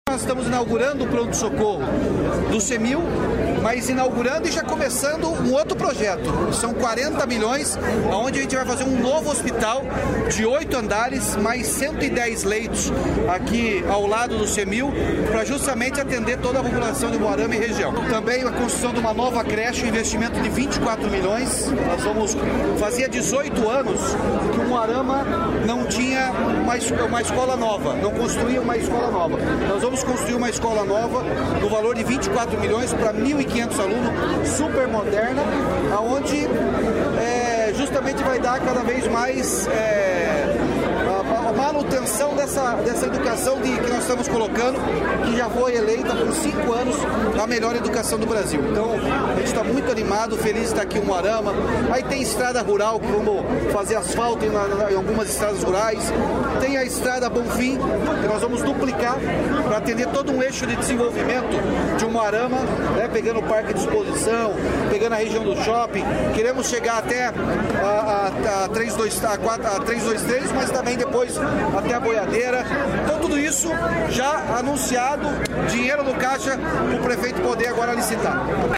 Sonora do governador Ratinho Junior sobre os investimentos de R$ 90 milhões em Umuarama